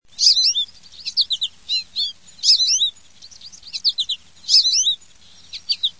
Звуки воробья
Шепот одинокого воробья